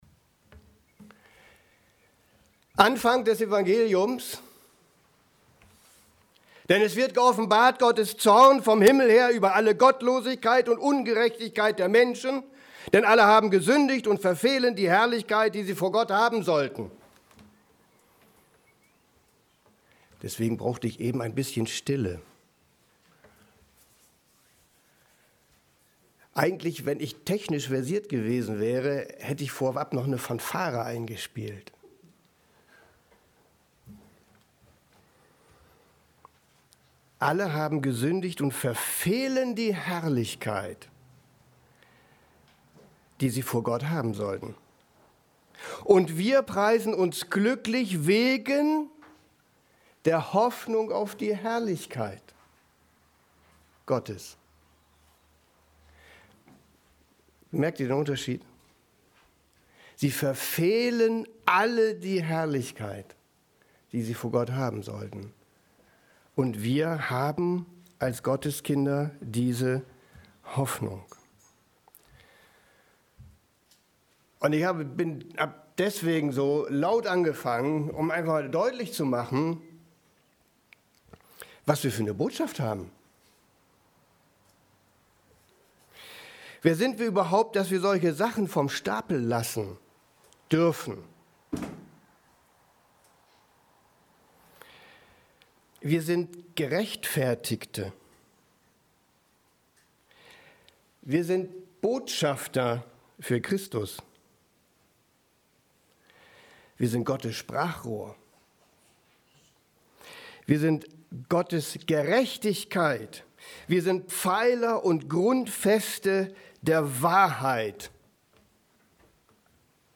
Predigt_14.06.2020_Römer_5,3-6